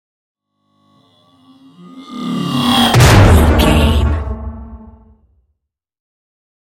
Sci fi whoosh to hit horror
Sound Effects
Atonal
dark
futuristic
intense
tension
woosh to hit